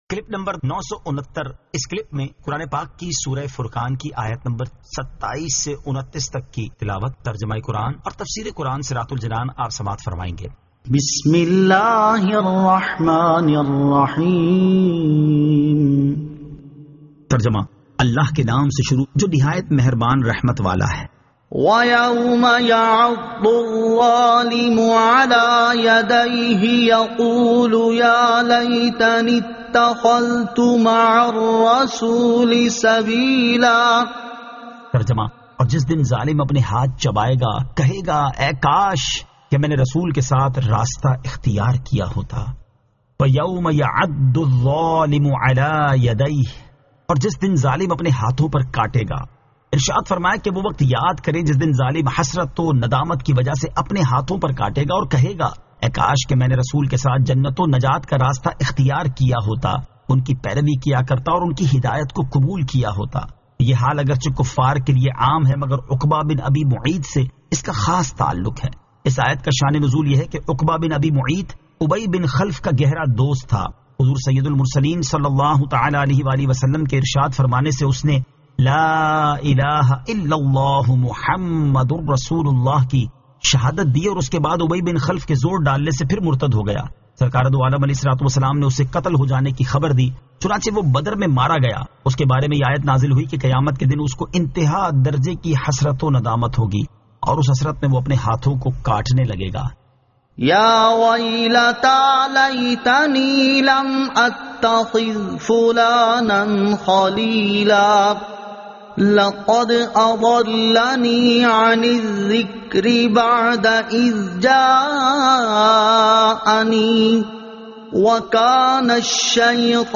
Surah Al-Furqan 27 To 29 Tilawat , Tarjama , Tafseer